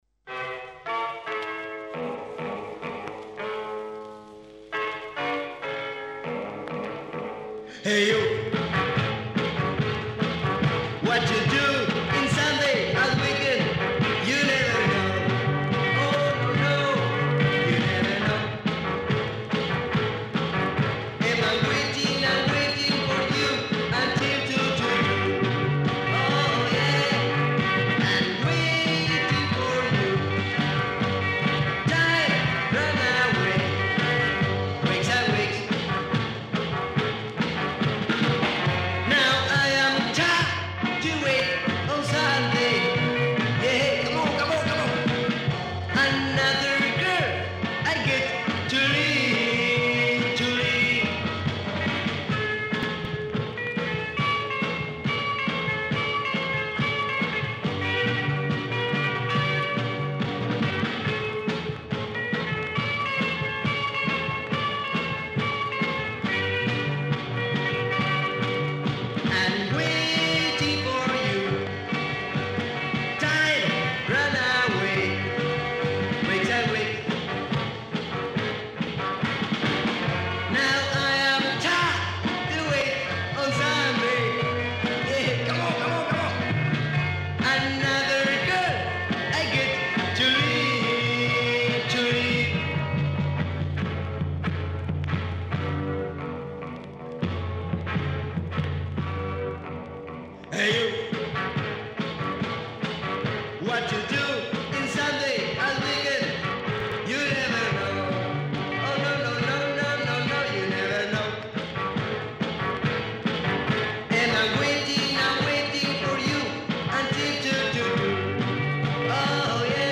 una columna llena de buena música en su entrega número catorce.<